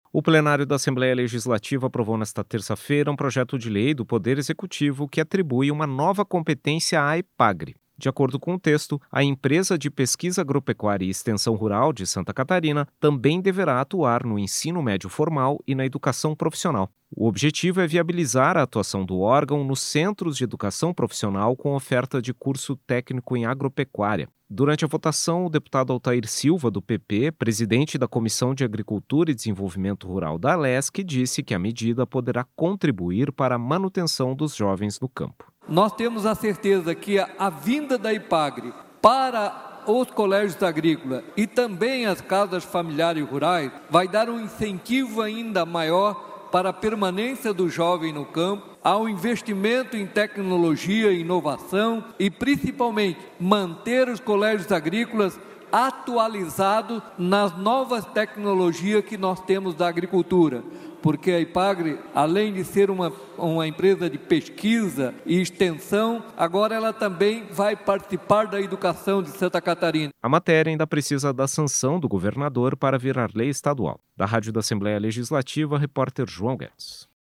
Entrevista com:
- deputado Altair Silva (PP), presidente da Comissão de Agricultura e Desenvolvimento Rural do Alesc.